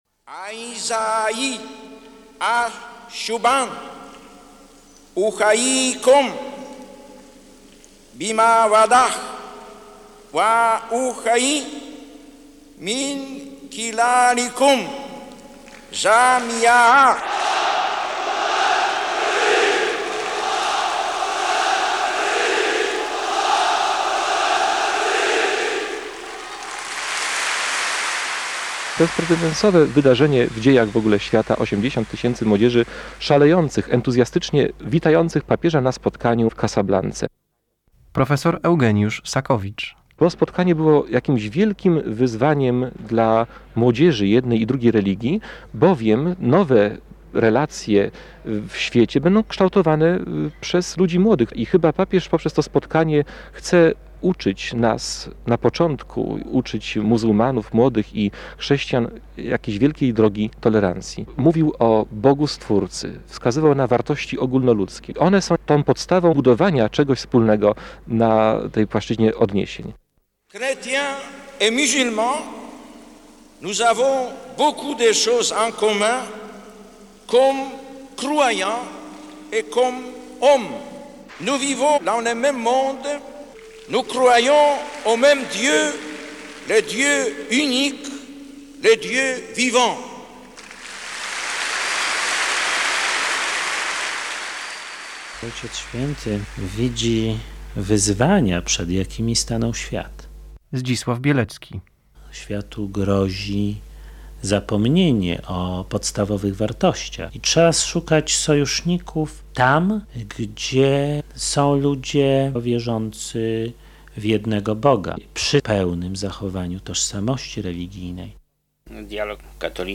Maroko 1985 r. - fragment audycji PR